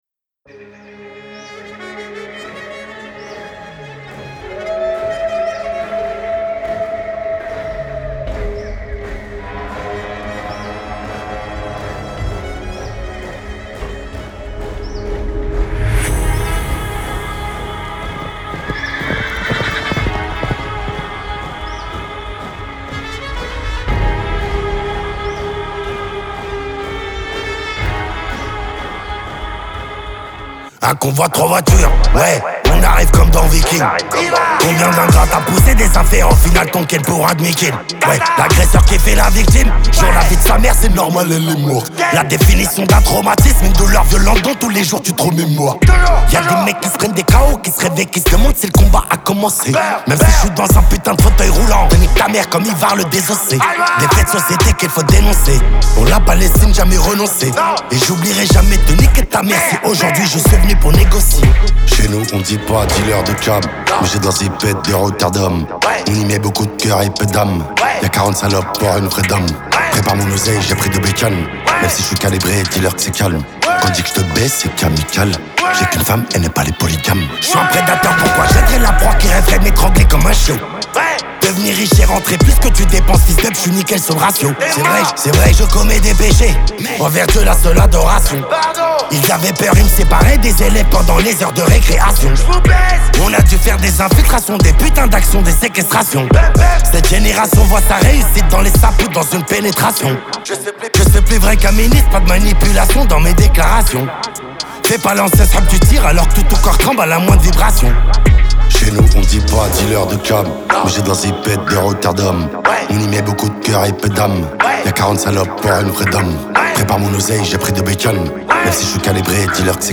26/100 Genres : french rap Télécharger